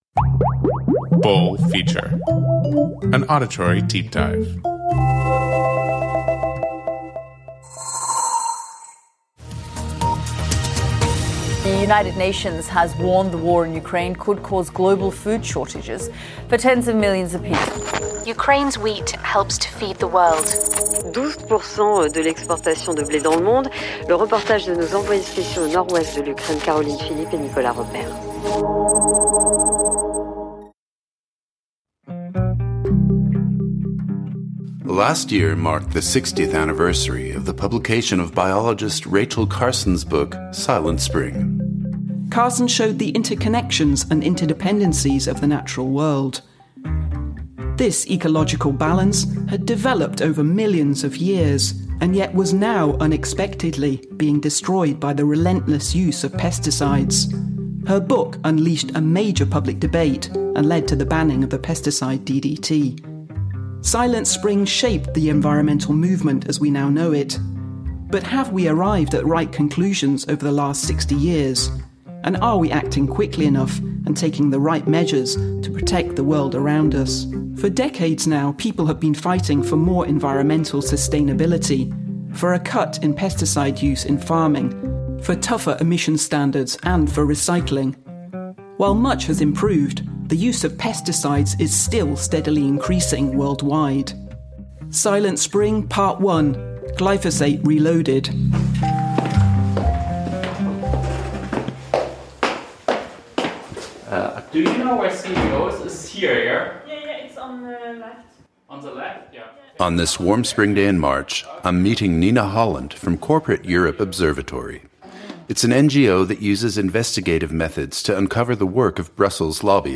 The conducted interviews and original sound recordings come from Europe and the USA.